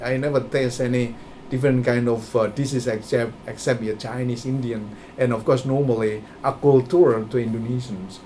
S1 = Taiwanese female S2 = Indonesian male Context: S2 is talking about what he ate when he was living in Cairo. S2 : ... i never taste any (.) different kind of er dishes except except er chinese india (.) and of course normally (aculture) to indonesians Intended Words: dishes Heard as: this is Discussion: The main problem with this word is that there is a medial [s] rather than [ʃ] .